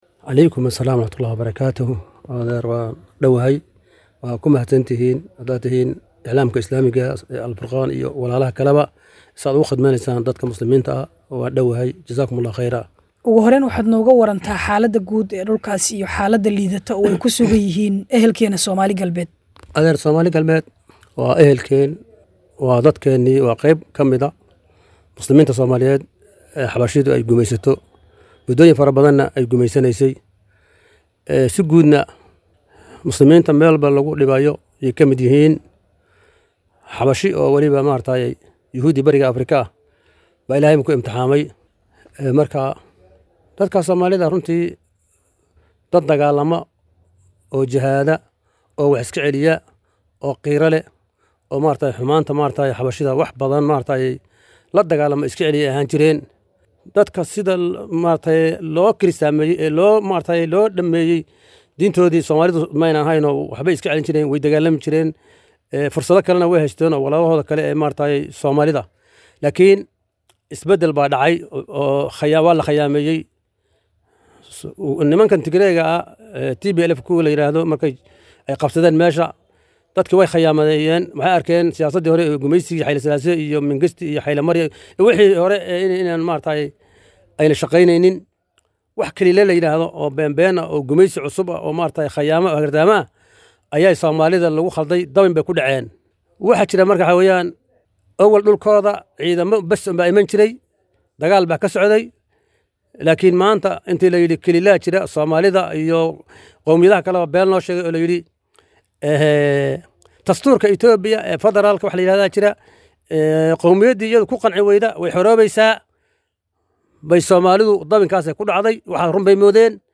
Maxaa la Gudboon Dadka ku Dhaqan Soomaali-galbeed oo ay Kusii Kordhayaan Tacadiyaadka.[WAREYSI]